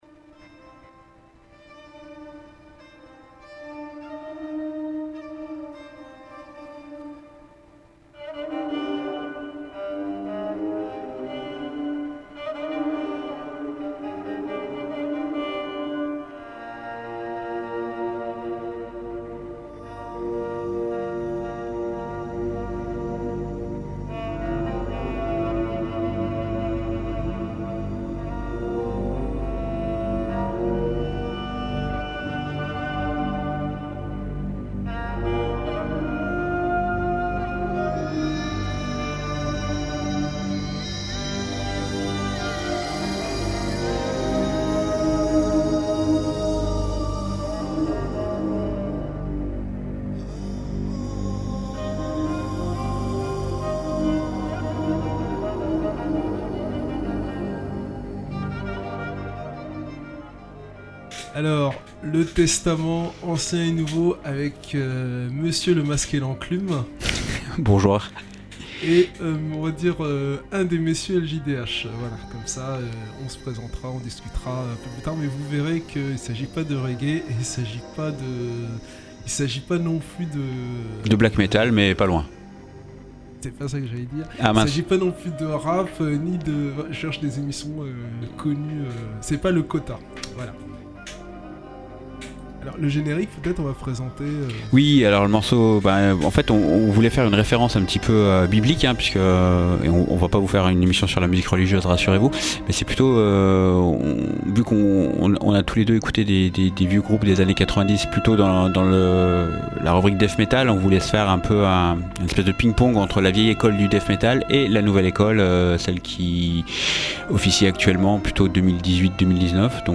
La crème de la vieille école (1998,1999) face à la fraîcheur de la nouvelle vague.(2018,2019). Résultat match nul : Du death metal et de la brutalité par paquets de 10!